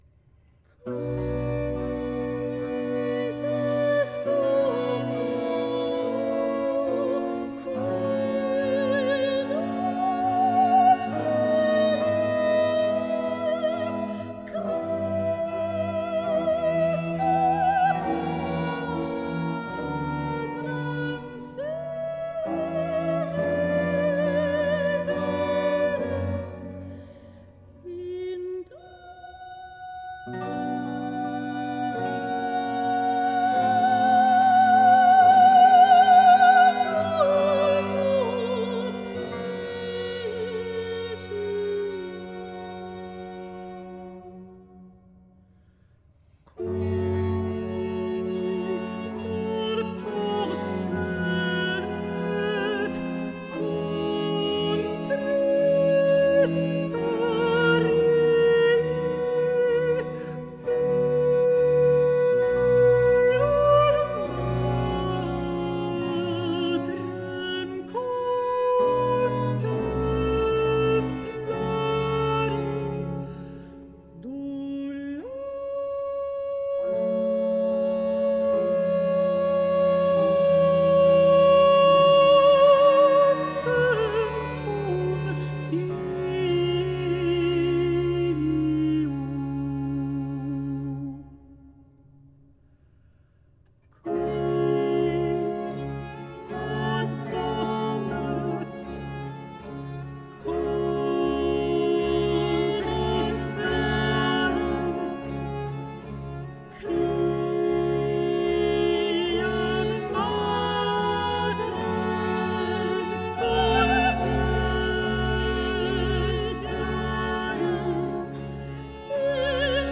très délicat duo